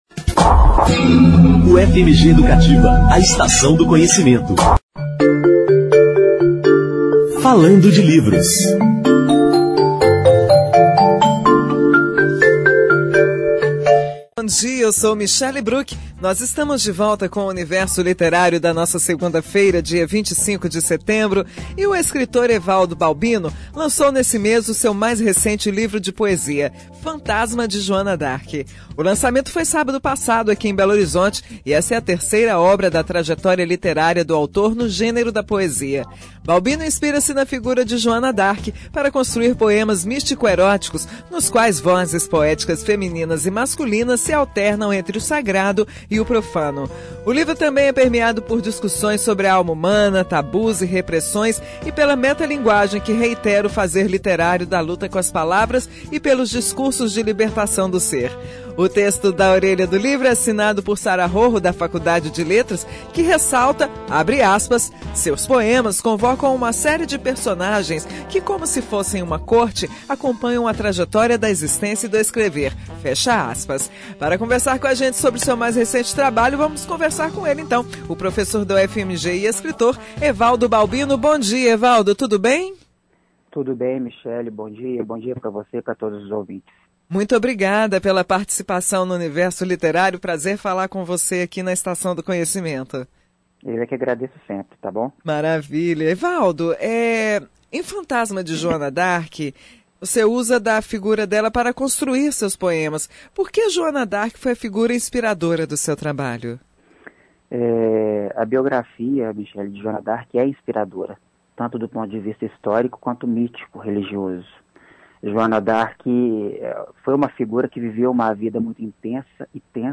A entrevista foi ao ar em 25/09/2017 no programa Universo Liter�rio.